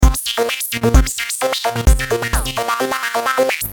Electro Tech-House Loop 130 BPM
This high-quality WAV delivers a driving 4/4 rhythm and gritty synth bass, perfect for ads, vlogs, or streaming content.
Genres: Synth Loops
Tempo: 130 bpm
Electro-tech-house-loop-130-BPM.mp3